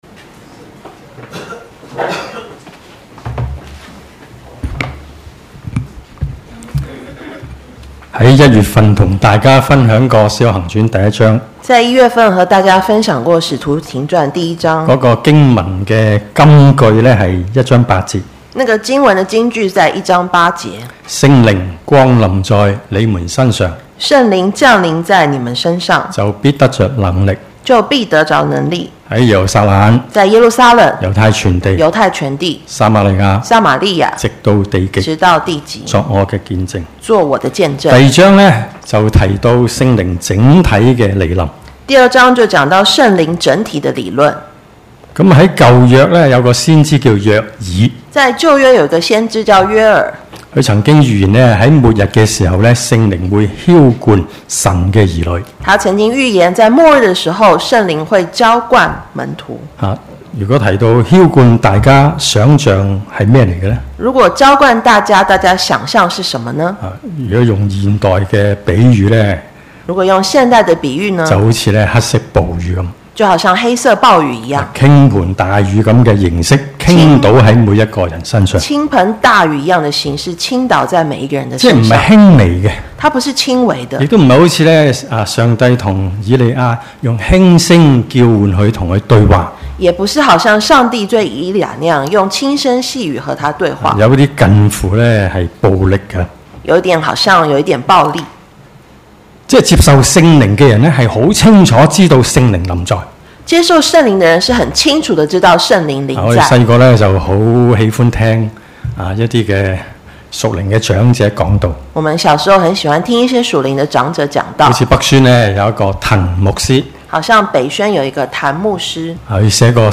那一天，門徒約添了三千人， Posted in 主日崇拜